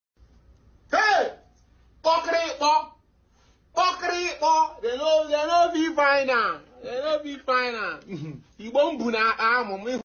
Kpokirikpo | Sound Effect For Comedy 🤣